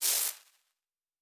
pgs/Assets/Audio/Sci-Fi Sounds/Electric/Spark 07.wav at 7452e70b8c5ad2f7daae623e1a952eb18c9caab4
Spark 07.wav